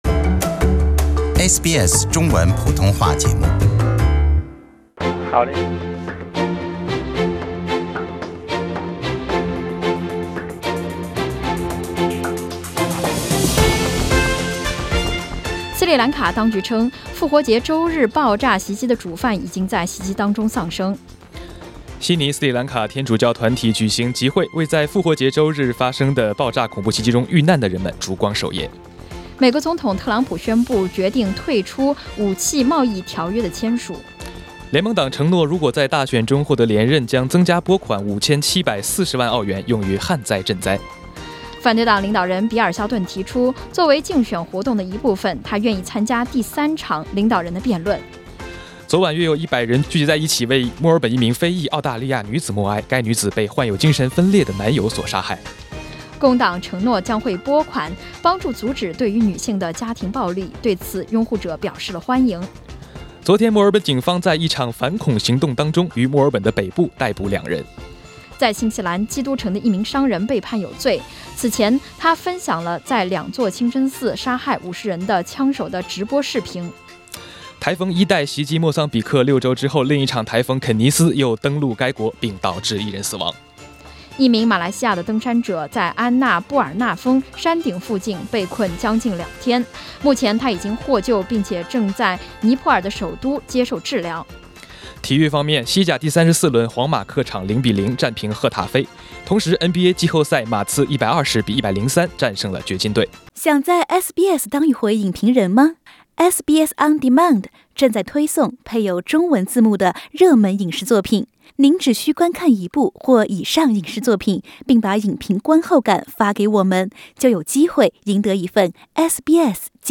SBS 早新闻 （4月27日）